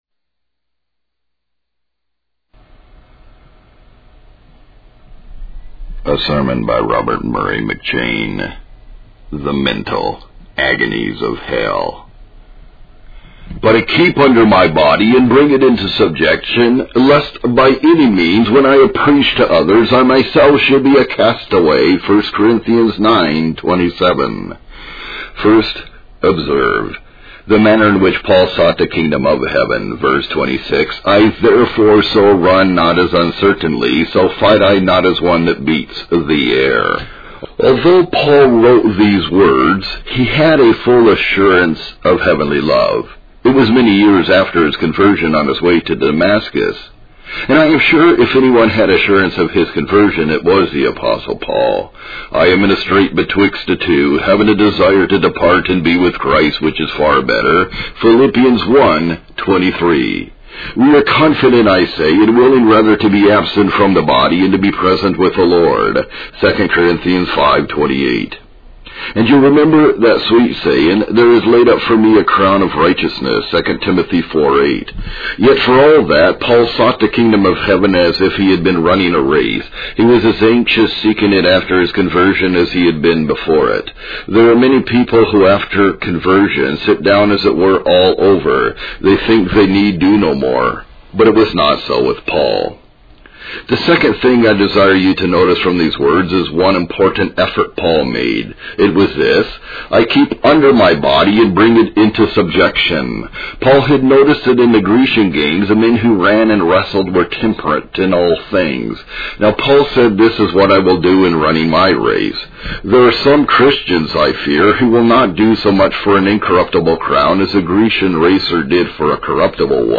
The video is a sermon by Robert Murray McChain on the topic of soul winning.